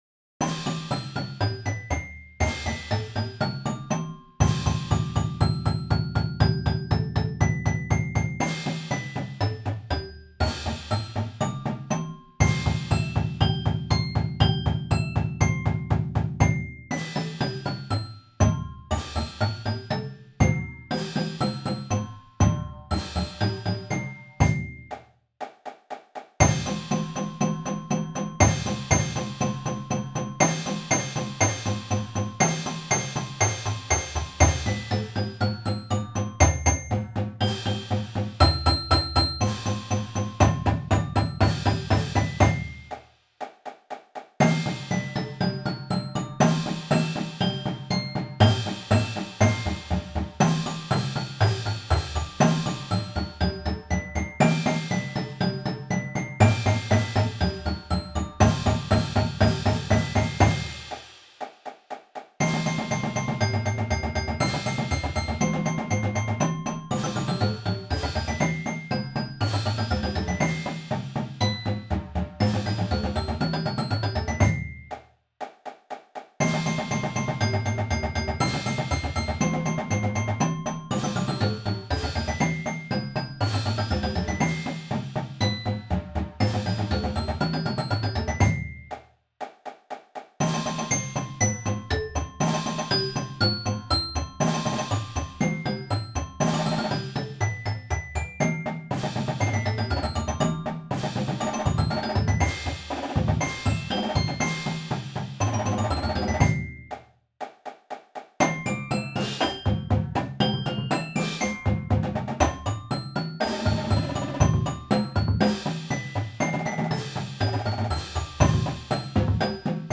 Voicing: Warm up